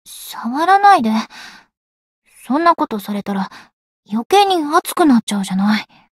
灵魂潮汐-星见亚砂-圣诞节（摸头语音）.ogg